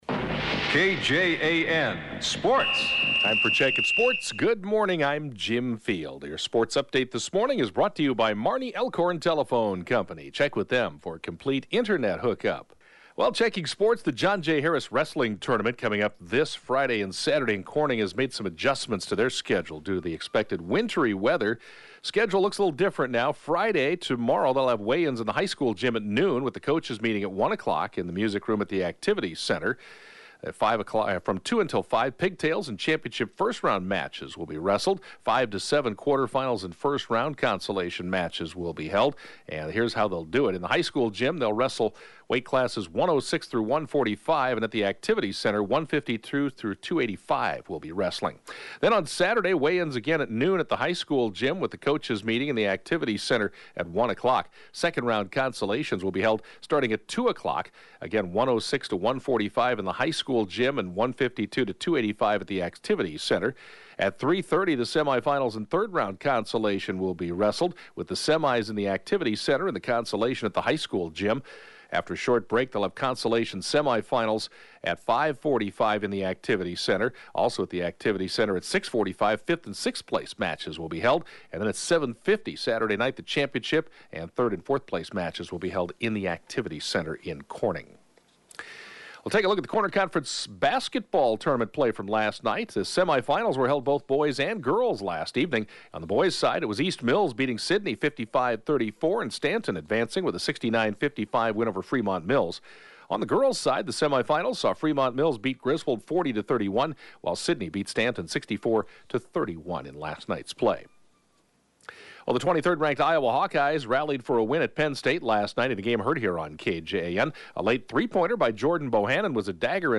7AM Sportscast 05/06/2017